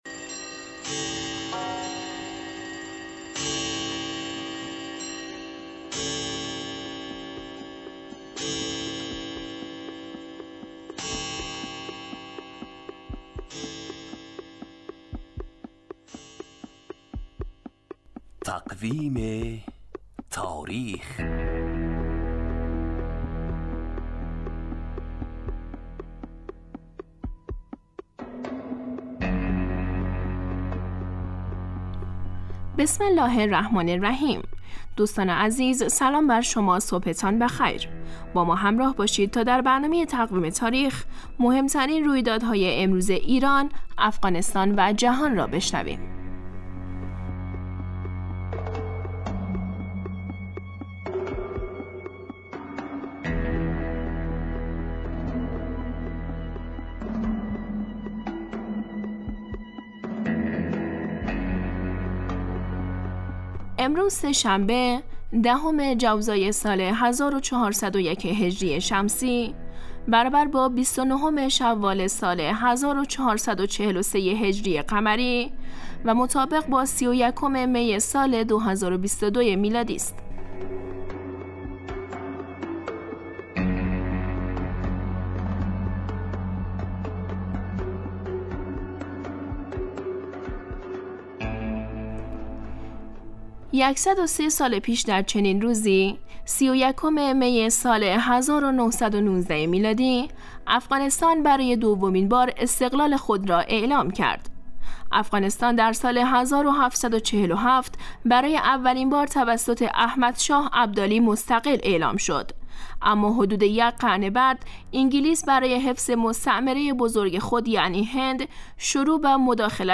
برنامه تقویم تاریخ هرروز ساعت 7:10 دقیقه به وقت افغانستان پخش میشود.